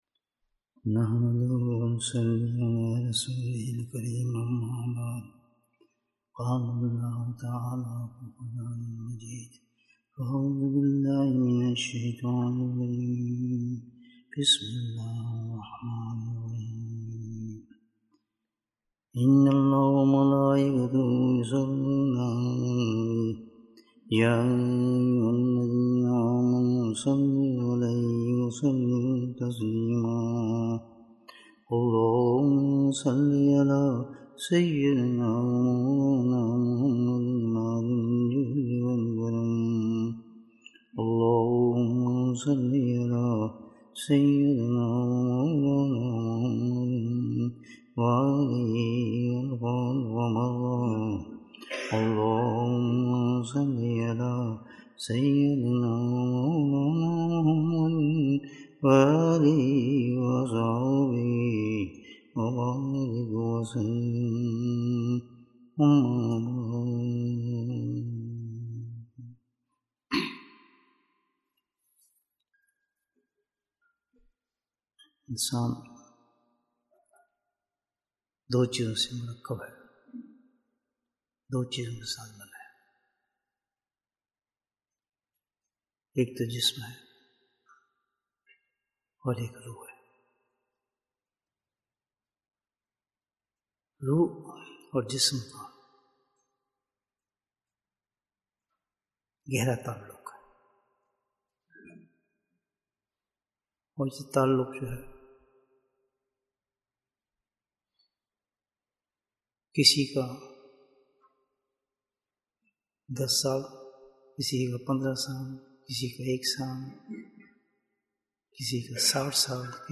Bayan, 71 minutes9th February, 2023